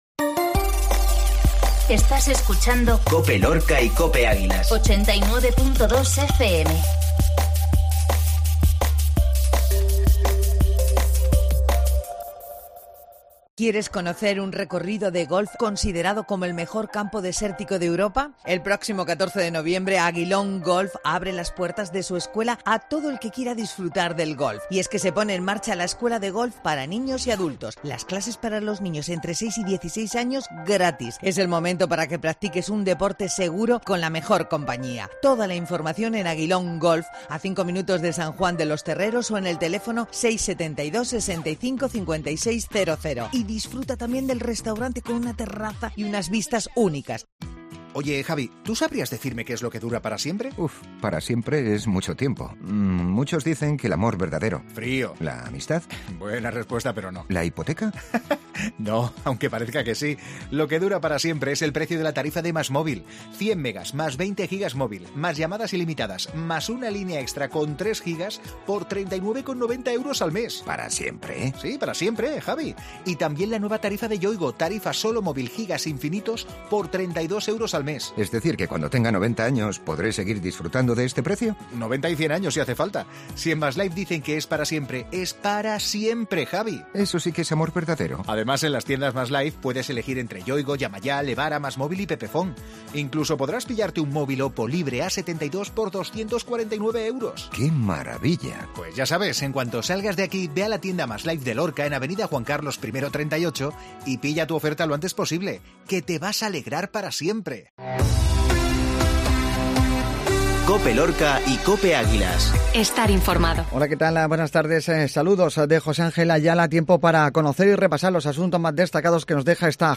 INFORMATIVO MEDIODIA COPE LORCA